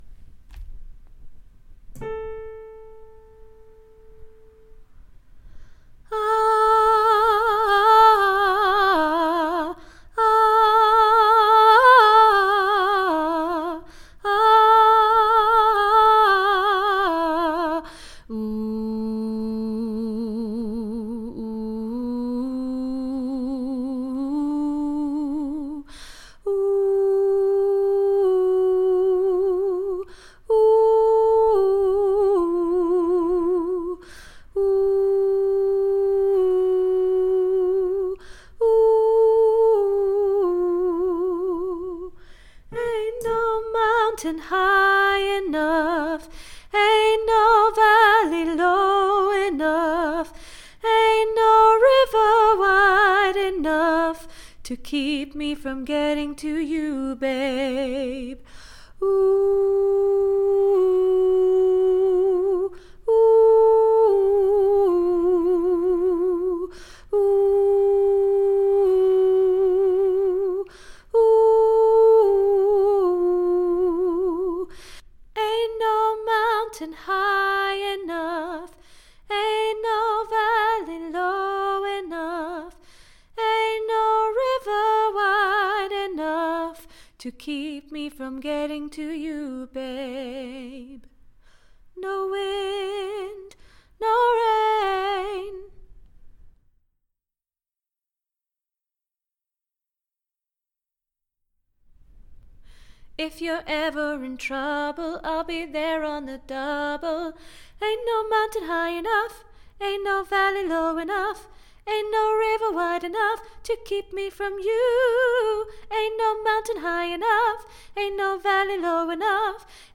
Ain't No Mountain Alto - Three Valleys Gospel Choir